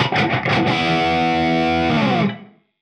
AM_HeroGuitar_85-E02.wav